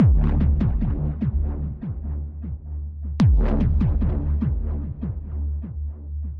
Tag: 75 bpm Weird Loops Fx Loops 1.08 MB wav Key : E